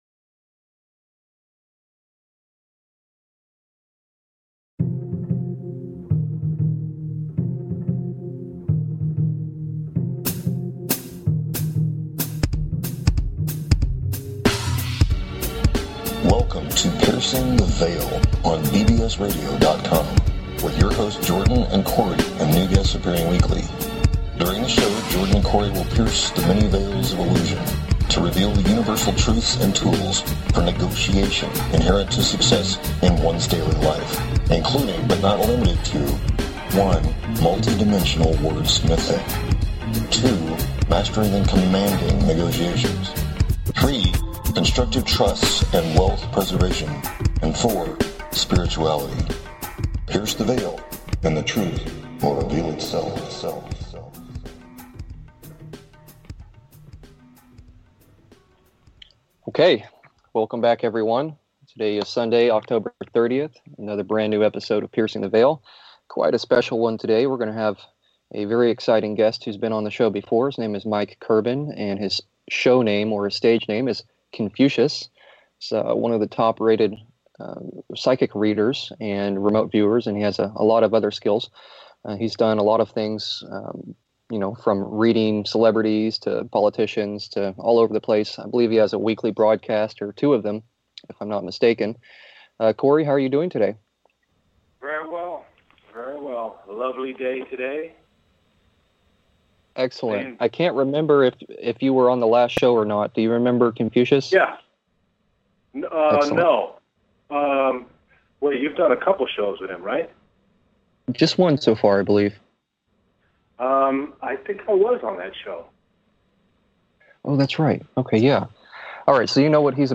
Show 36- Callers Get Free Psychic Readings, October 30, 2016
Talk Show Episode